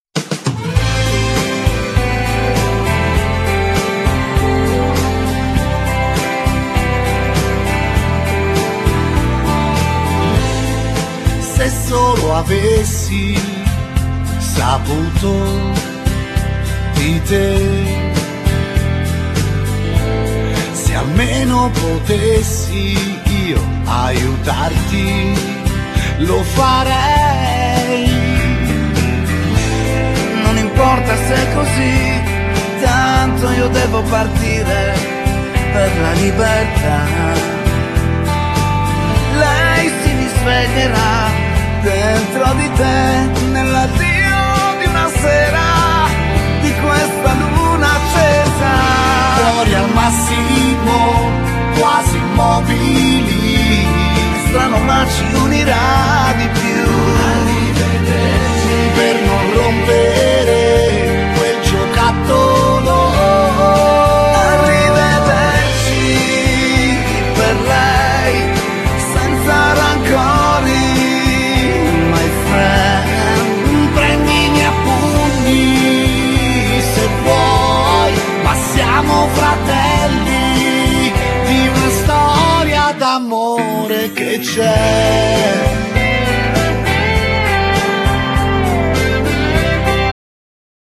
Genere: Pop